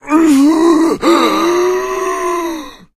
fracture_attack_1.ogg